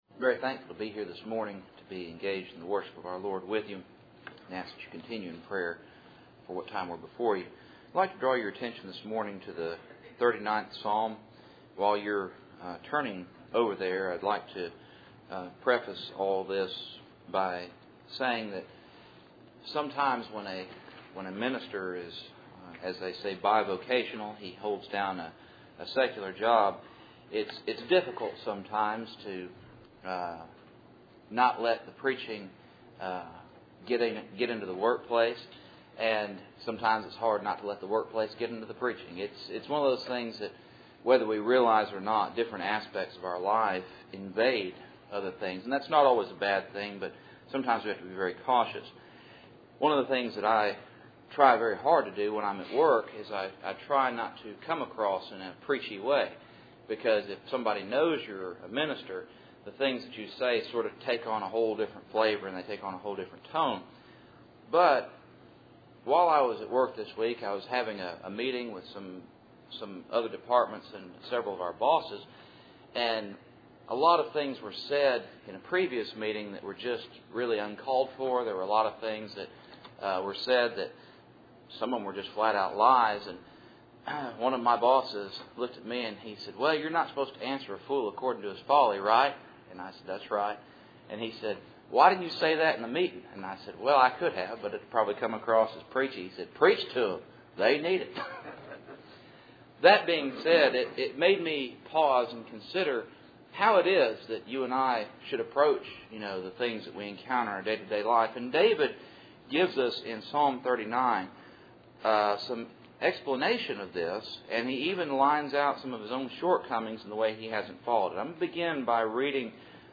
Cool Springs PBC 1st Saturday %todo_render% « Promises of a Cross